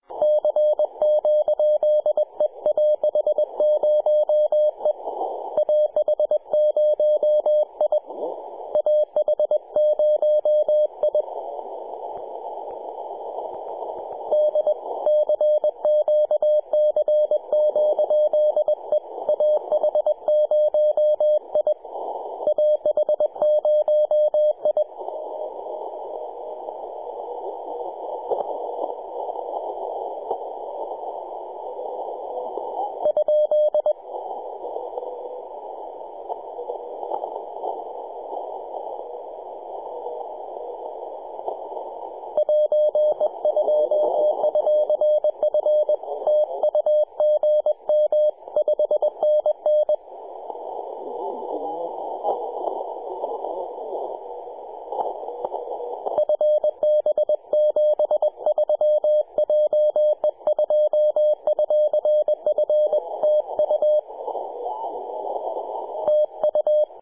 10.105MHz CW